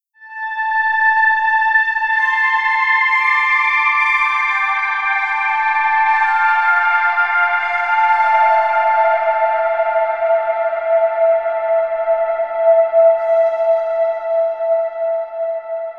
Synth 29.wav